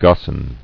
[gos·san]